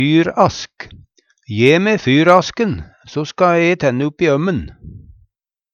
fyrask - Numedalsmål (en-US)